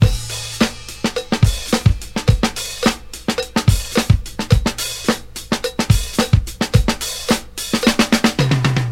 • 108 Bpm Drum Groove A Key.wav
Free breakbeat - kick tuned to the A note. Loudest frequency: 1040Hz
108-bpm-drum-groove-a-key-qN3.wav